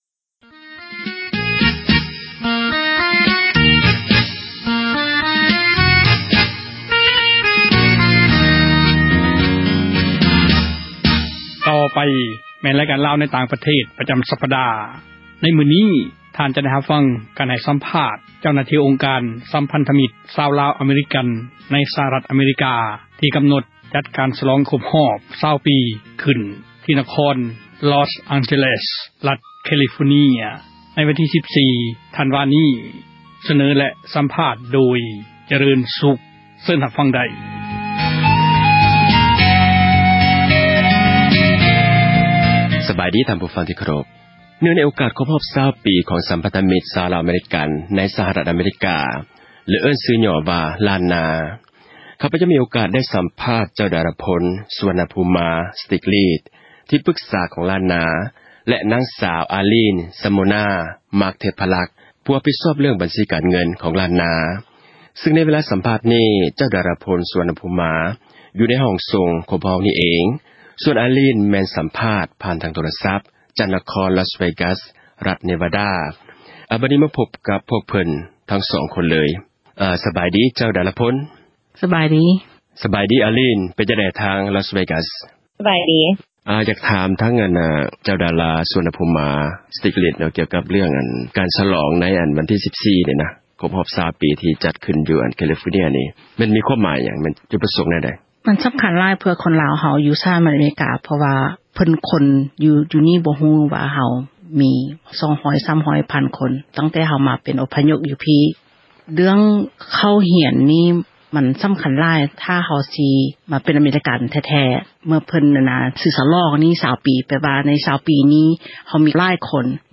ຢູ່ໃນຫ້ອງສົ່ງຂອງພວກເຮົາ
ແມ່ນສັມພາດ ຜ່ານທາງໂທຣະສັບ